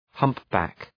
Προφορά
{‘hʌmp,bæk}